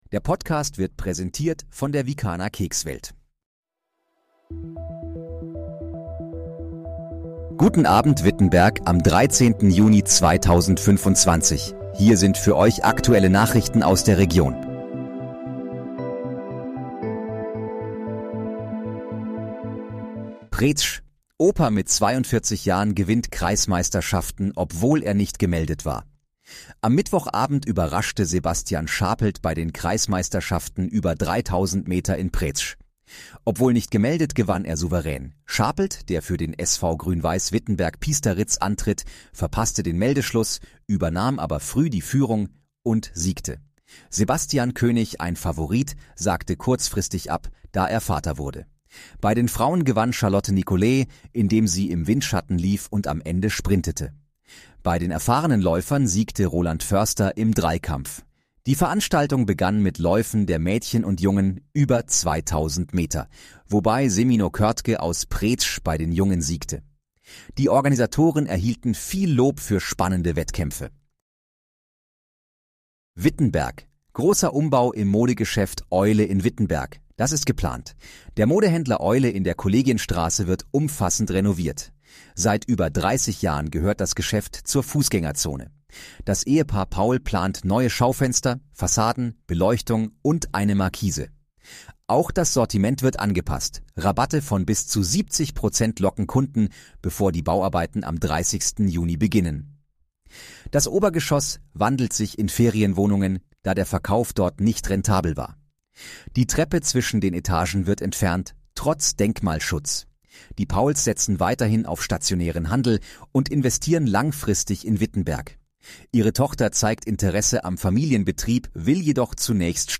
Guten Abend, Wittenberg: Aktuelle Nachrichten vom 13.06.2025, erstellt mit KI-Unterstützung
Nachrichten